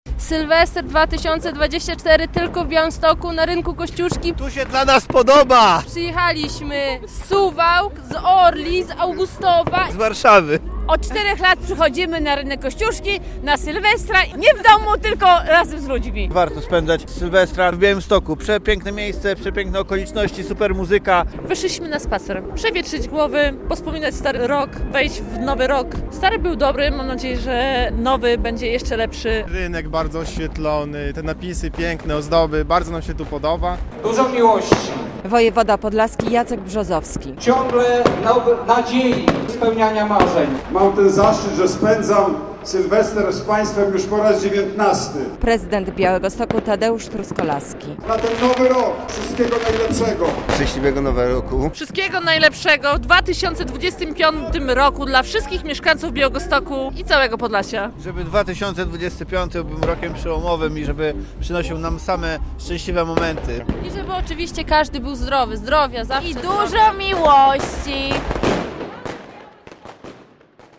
W Białymstoku przywitano rok 2025 - relacja